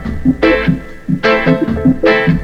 RAGGA LP01-R.wav